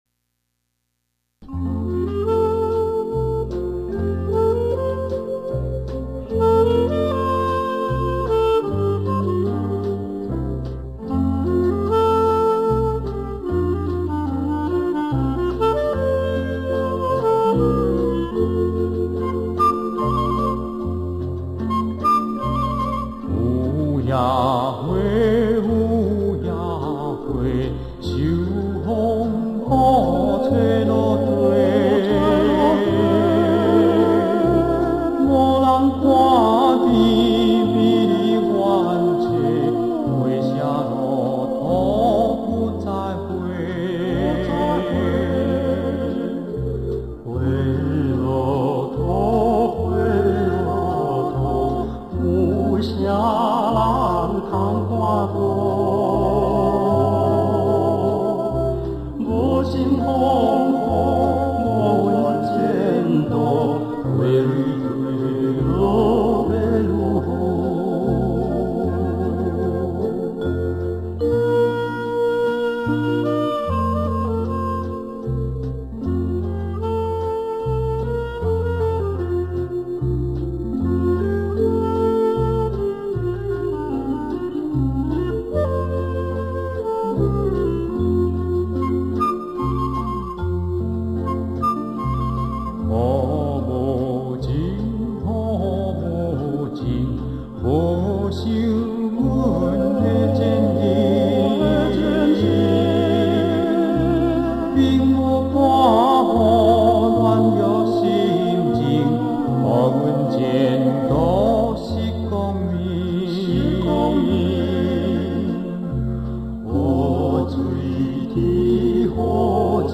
闽南语专辑